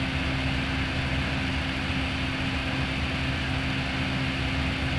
IDG-A32X/Sounds/SASA/CFM56/cockpit/cfm-idle.wav at 74155d36eab22b546e232031e14ba997be0c31cd
cfm-idle.wav